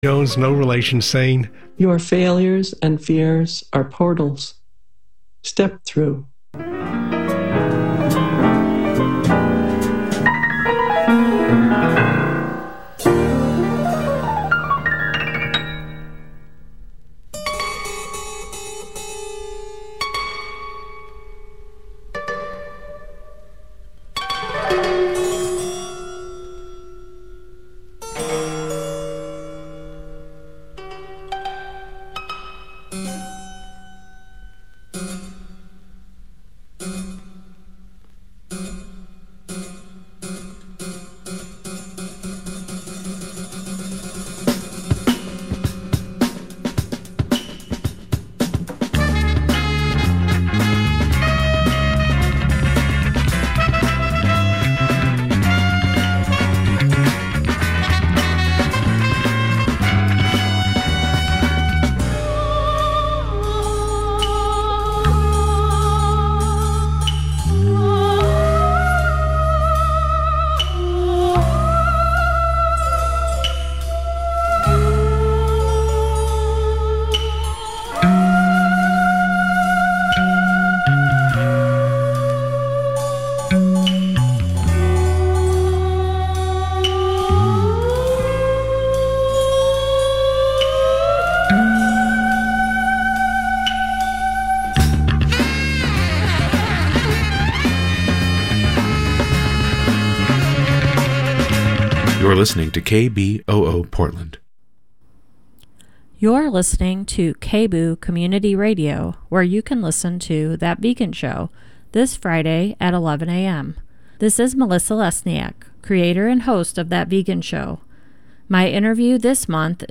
We’re also joined by two animation directors who’ll be appearing at the festival, both of whom work in traditional animation fields.